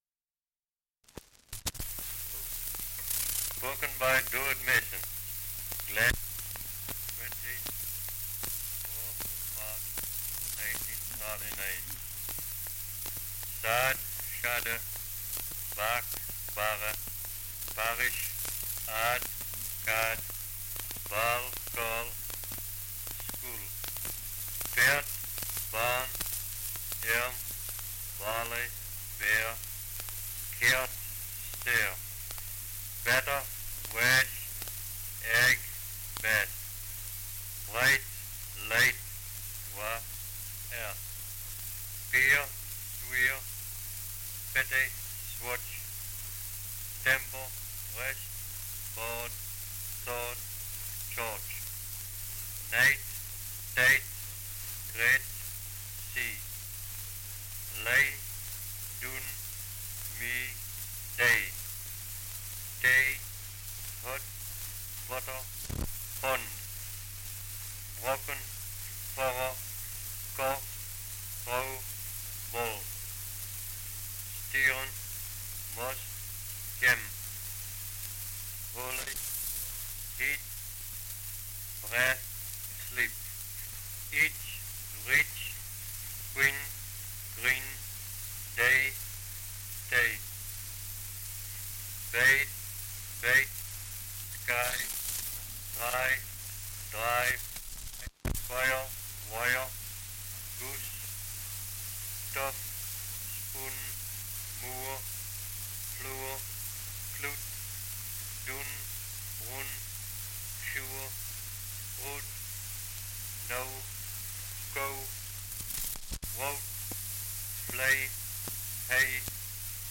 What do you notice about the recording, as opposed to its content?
Dialect recording in Northumberland 78 r.p.m., cellulose nitrate on aluminium